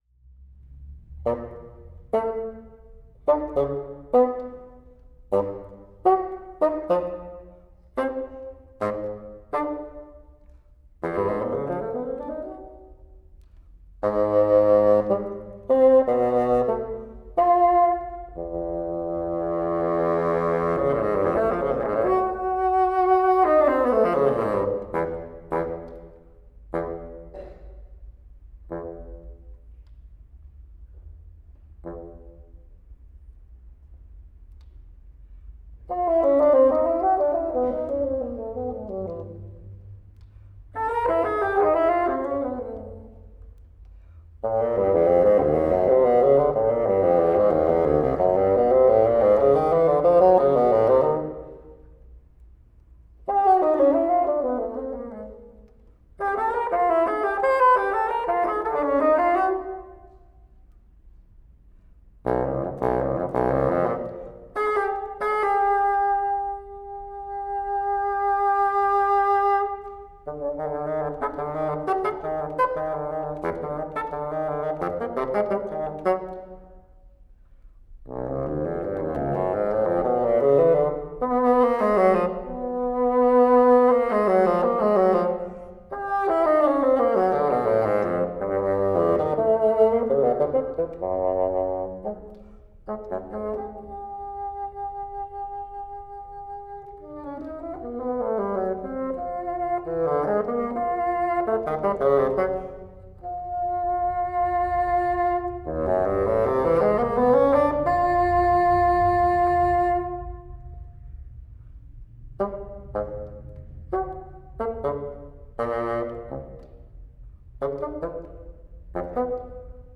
DLA koncert live concert